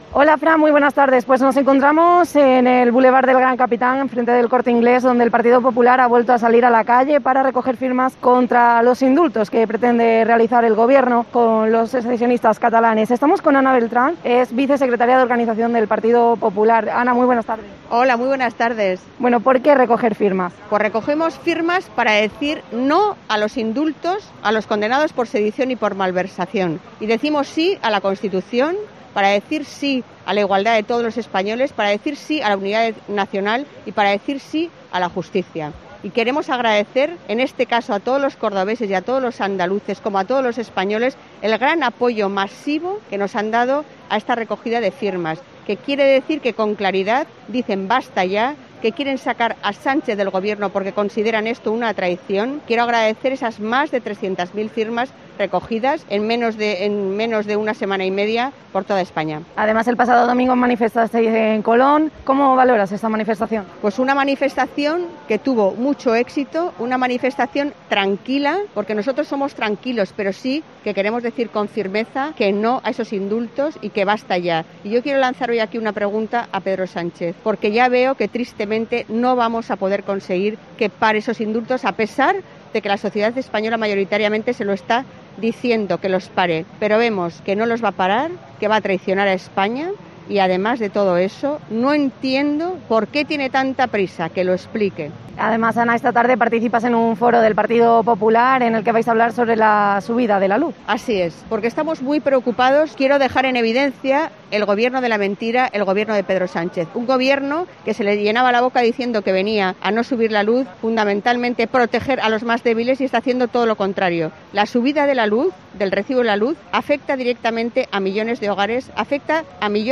Escucha la entrevista a la vicesecretaria de organización del PP, Ana Beltrán